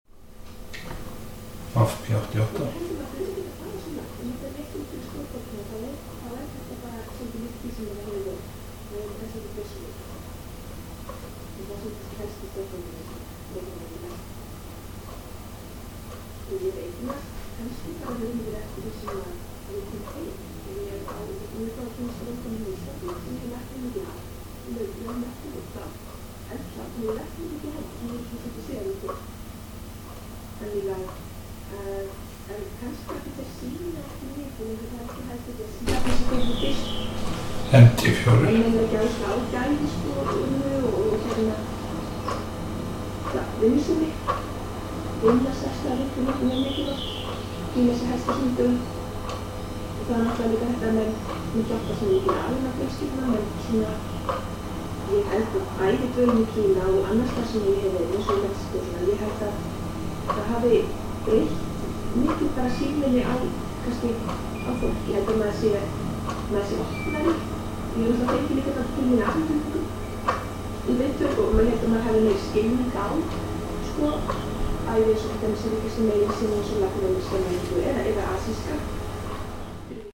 Ég stillti hann á víða steríómynd og prófaði hann samhliða NT4. Stillti ég þeim báðum á sama stað á borði þar sem þeir lágu á púða. Í herberginu var lágt stillt útvarp í gangi í um þriggja metra fjarlægð. Veggklukka tifaði á vegg í tveggja metra fjarlægð og kæliskápur var í gangi í þriggja metra fjarlægð. Hljóðnemarnir voru báðir tengdir við Sound device 305 formagnara þar sem slökt var á hljóðsíum og styrkur hafður í botni til að fá fram grunnsuðið. Tekið var upp á Korg MR1000 upptökutæki. Sjálfur kynni ég svo hljóðnemana þar sem ég sit einn metra fyrir aftan þá. Hljóðdæmið gefur ekki fullkomna mynd af þessum tveimur hljóðnemum en segir þó til um suð og næmni.
All filters at zero and gain and faders at 100%.